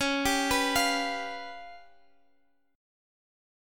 Listen to Db7b5 strummed